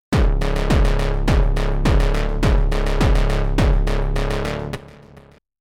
here’s another one, no FM this time, with a static pulse width change to the two saws